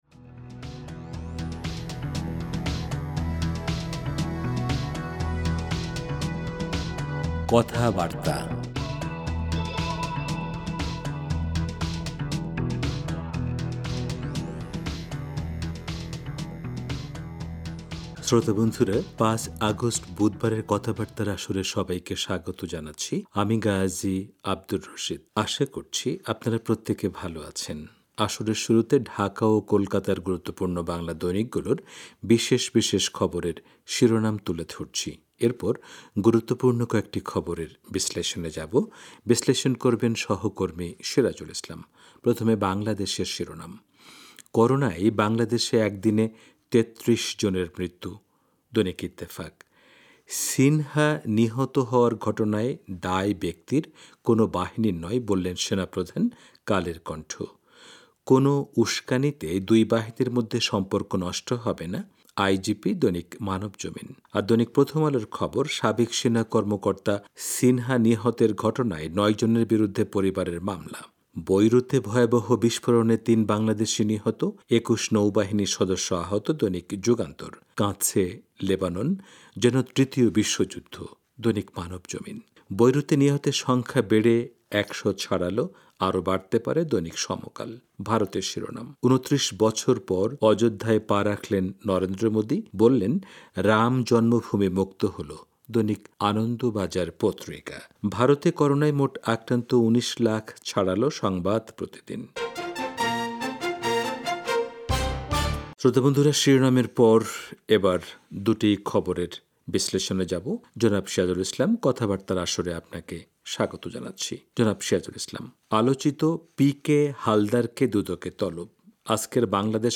রেডিও